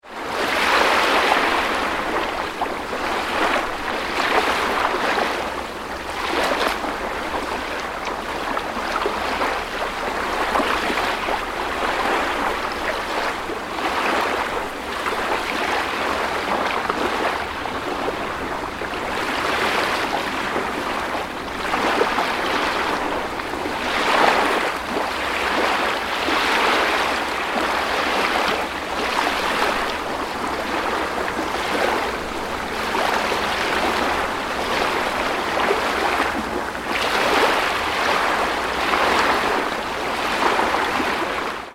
Thai waves